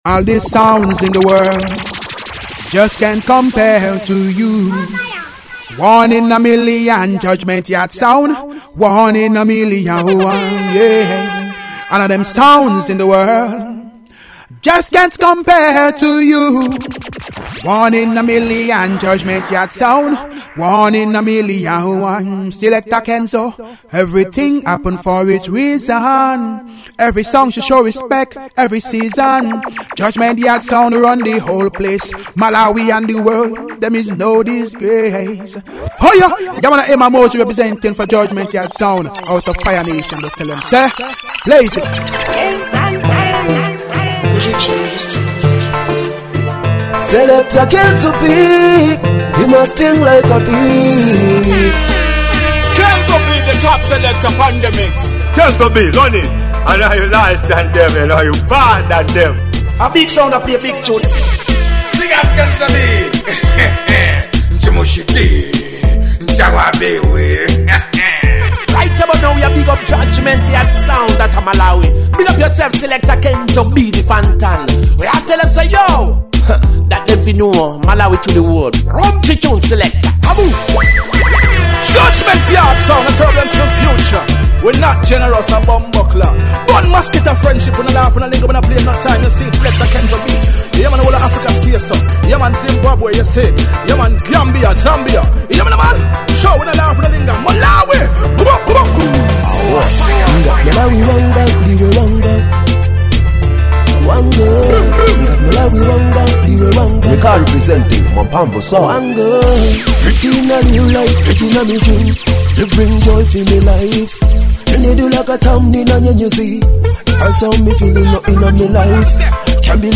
Dancehall